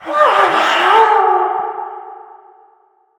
balloon_ghost_wail_01.ogg